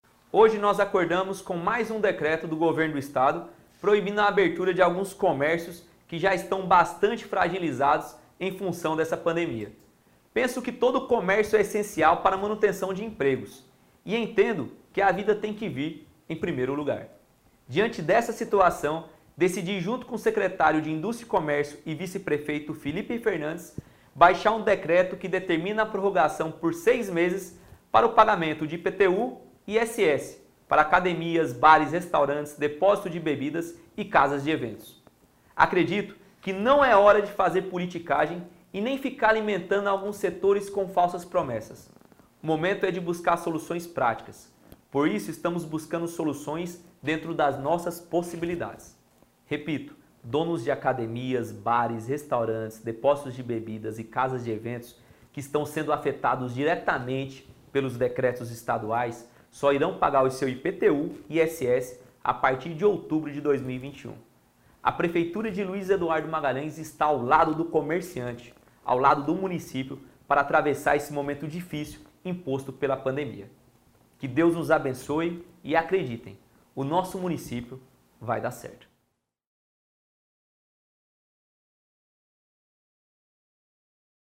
Acesse nosso podcast e ouça o Prefeito Júnior Marabá.